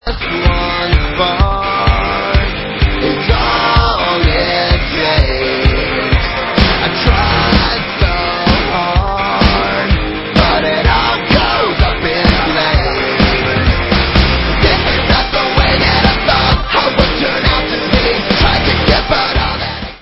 sledovat novinky v oddělení Alternative Rock
Rock